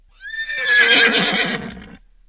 Neigh2.wav